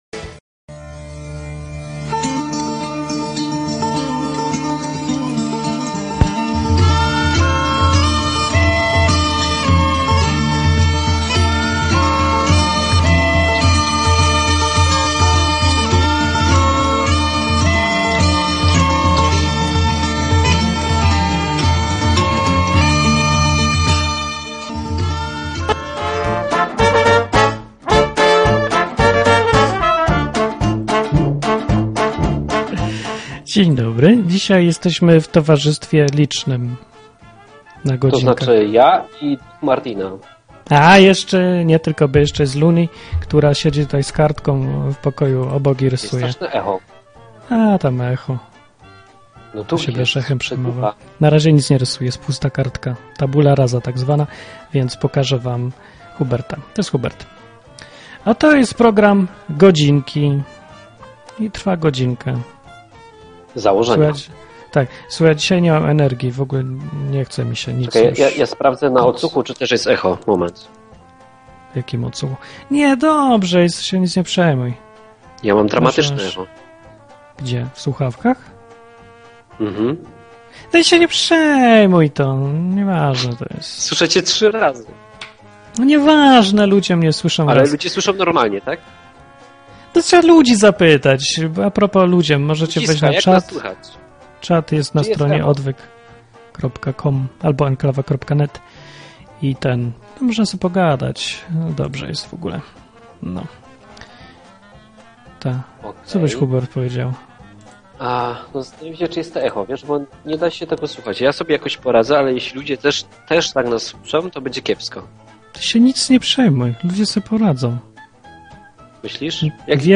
Rozmowy ze słuchaczami na tematy Biblii, Boga, księdza, egzorcyzmów.